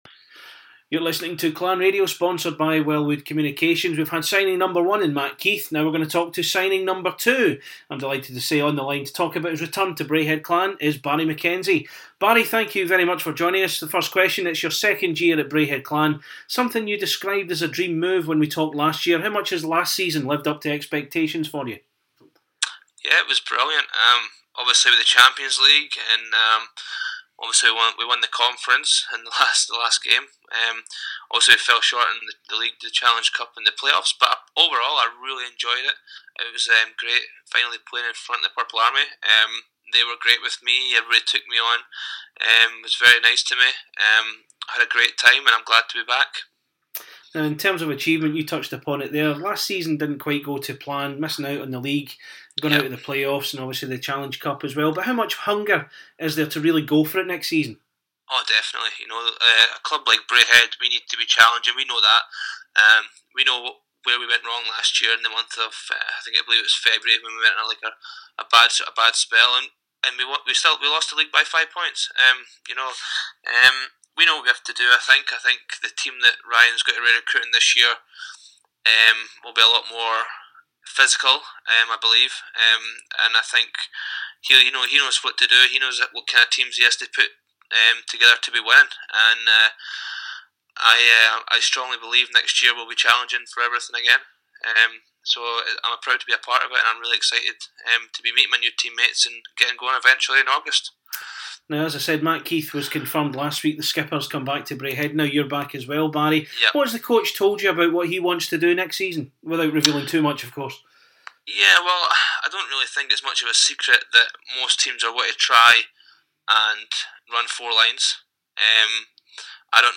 Clan Chat / INTERVIEW